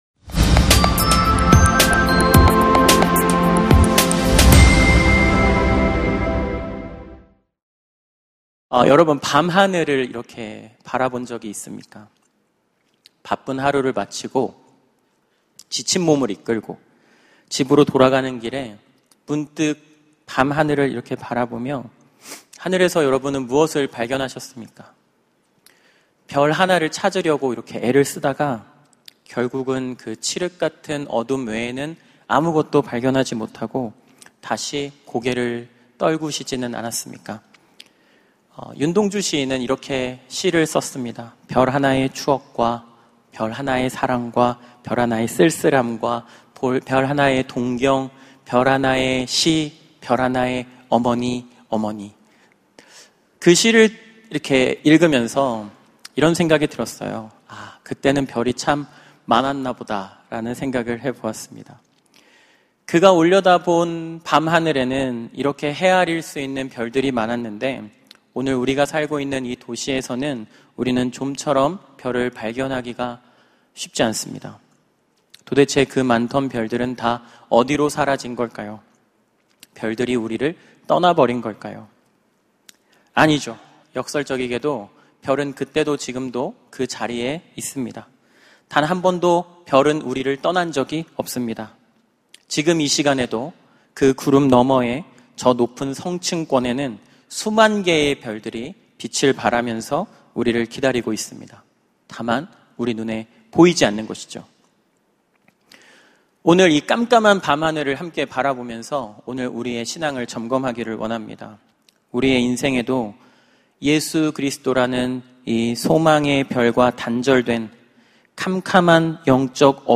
설교